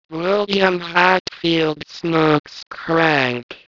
Bell Labs' Text-to-Speech- Just type in what you want to hear and choose your file type and it will give the audio in one of eight user-specified voices.